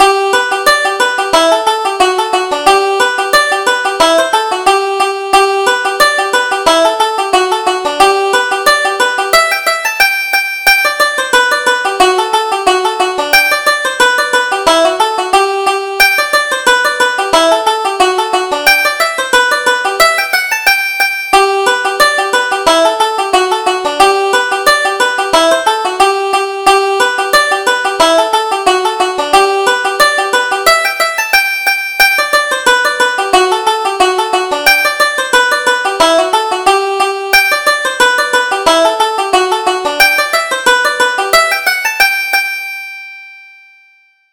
Reel: The Belfast Lasses